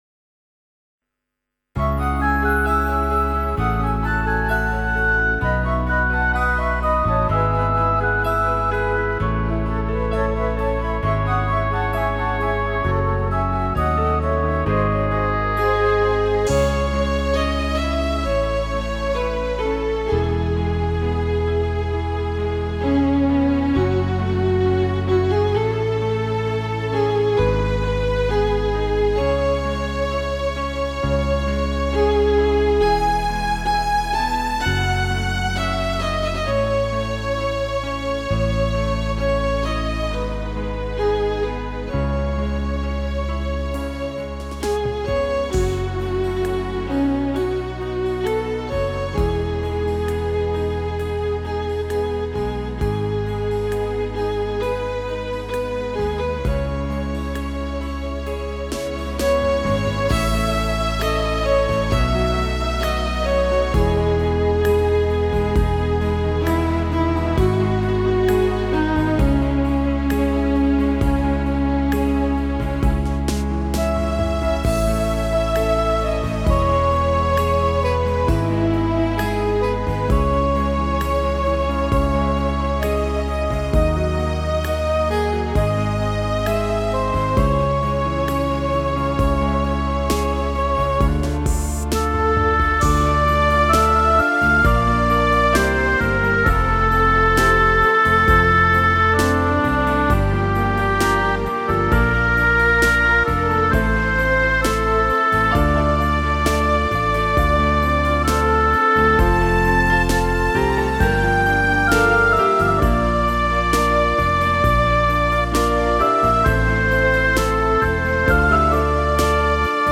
Thể Loại Đạo Ca: Đạo ca Cao Đài